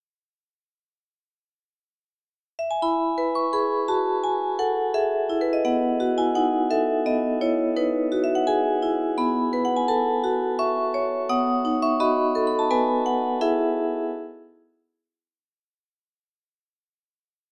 Music Box “Starling Nesting Box” with Two Children, with 18-note Musical Movement